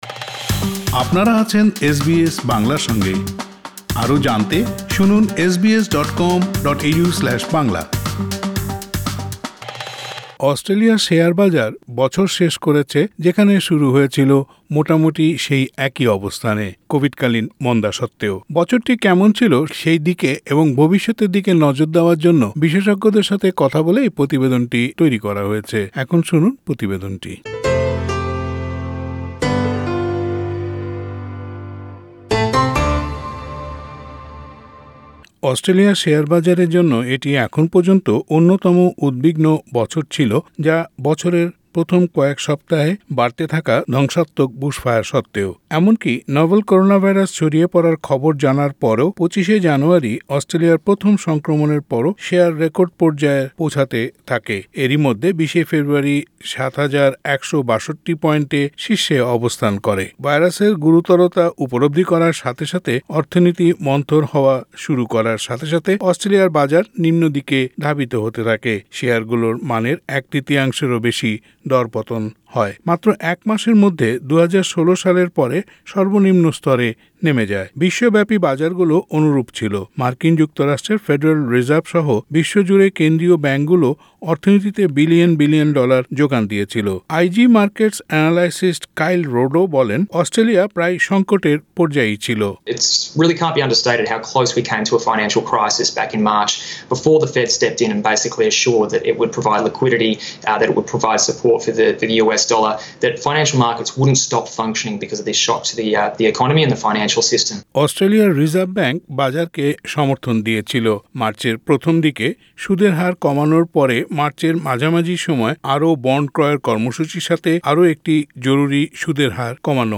বছরটি কেমন ছিল সেই দিকে এবং ভবিষ্যতের দিকে নজর দেওয়ার জন্য বিশেষজ্ঞদের সাথে কথা বলে এই প্রতিবেদনটি তৈরি করা হয়েছে।